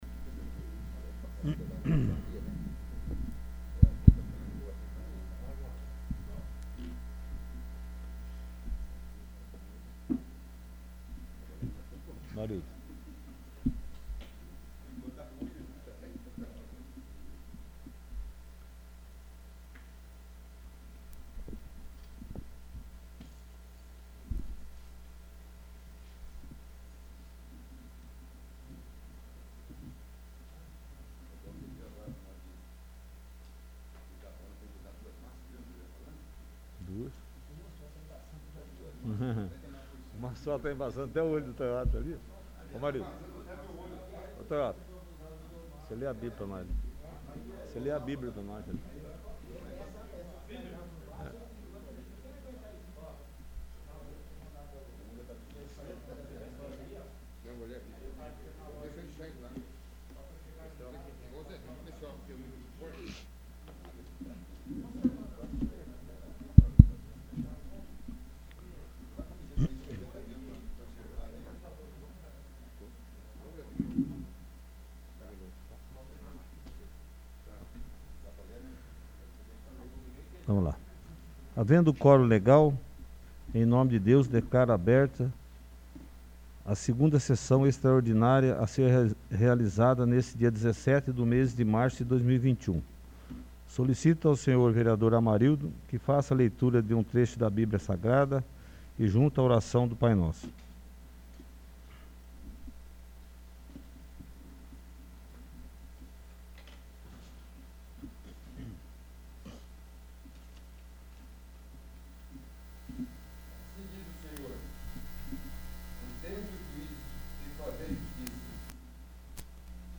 2º. Sessão Extraordinária